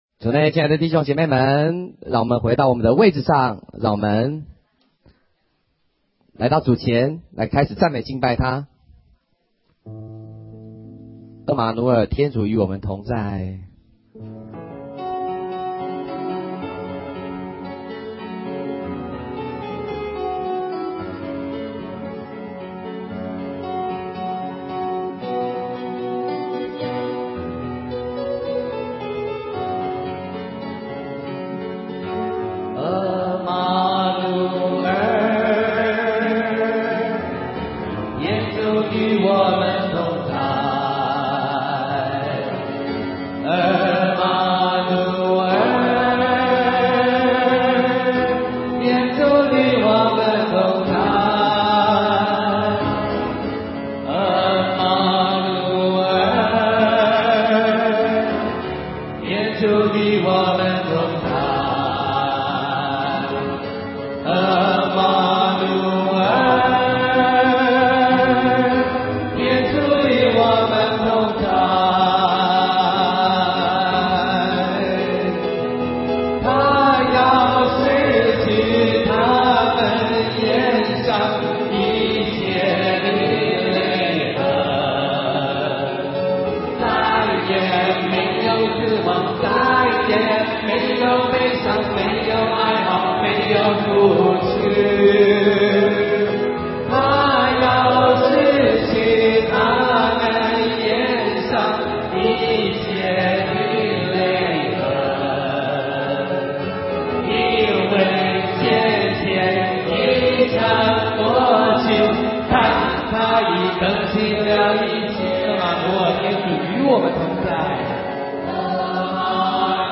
音频/讲道/神恩/07 真福八端 • Directory Lister